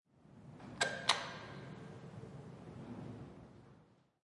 冰岛大气和现场录音 " 在湖边，汽车，天鹅和鸭子
描述：记录在冰岛Hafnarfjörður湖附近，放大H4n。
标签： 鸟类 天鹅 环境 冰岛 平静 街道 汽车 现场录音 城市
声道立体声